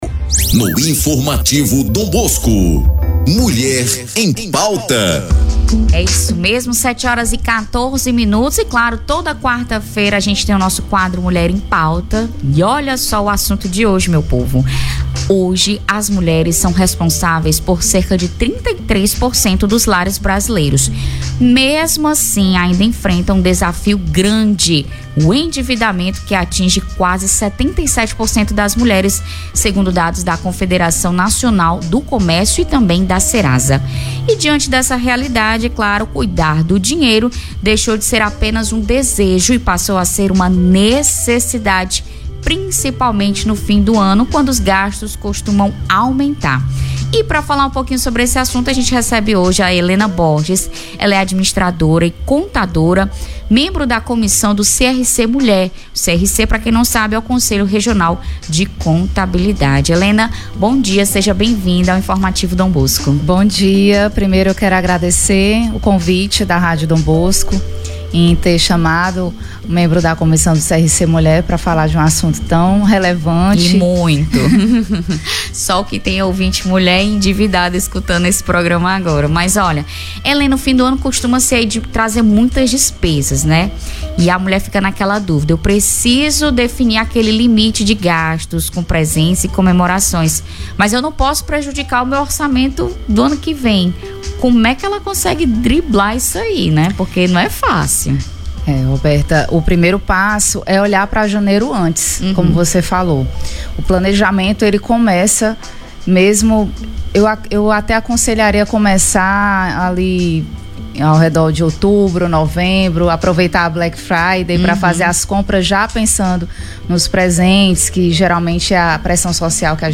ENTREVISTA-1712.mp3